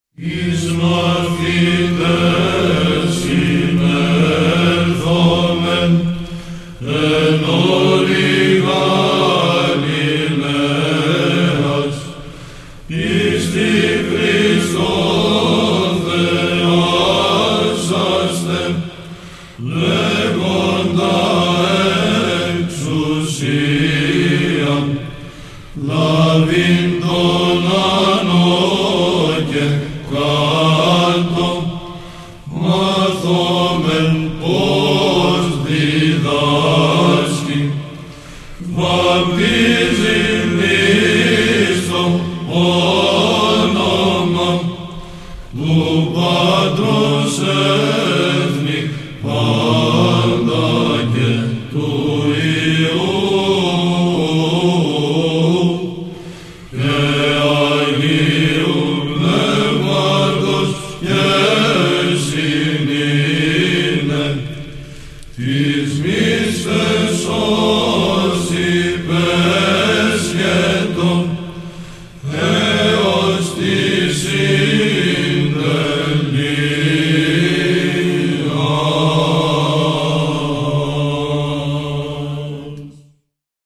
Ήχος β'